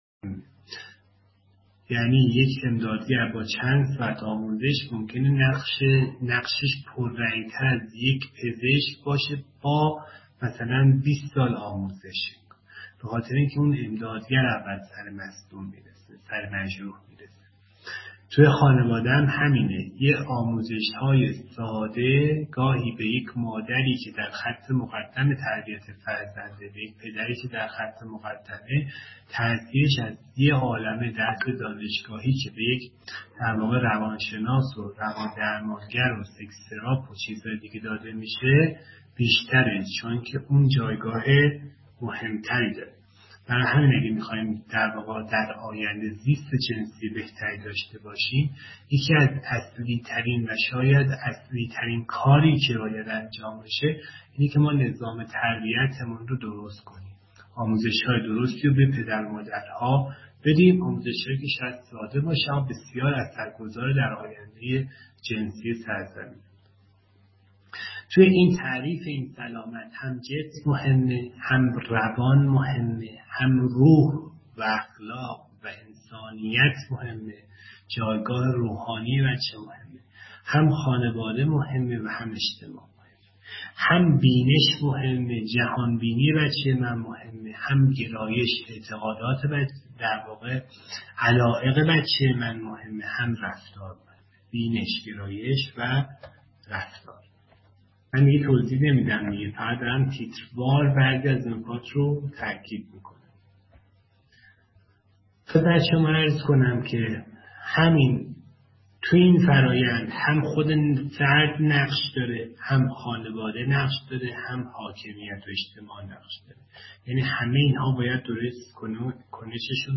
به همت معاونت فرهنگی دانشکده توان‌بخشی و دفتر هم‌اندیشی استادان نهاد نمایندگی مقام معظم رهبری در دانشگاه، کارگاه سلامت جنسی فرزندان ویژه استادان دانشگاه، روز سه‌شنبه 7 دی‌ماه 1400 به‌صورت مجازی و بر بستر سامانه اسکای‌روم برگزار شد.